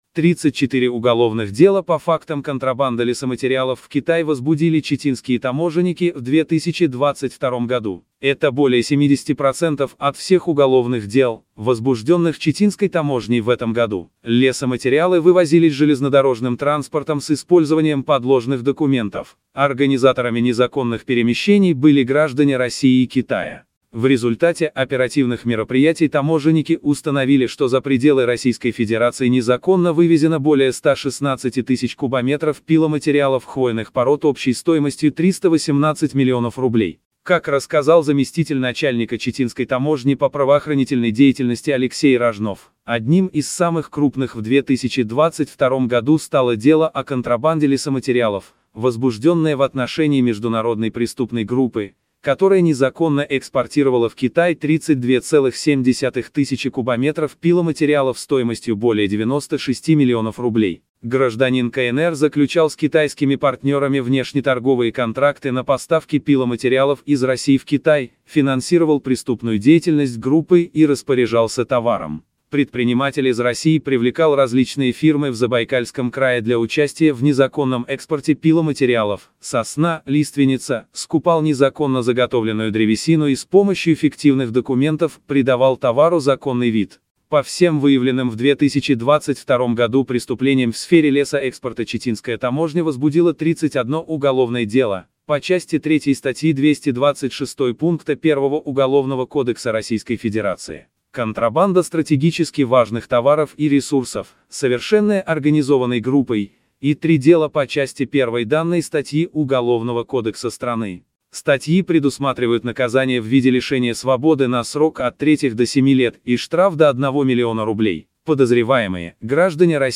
Нет времени читать новость, прослушайте электронную версию.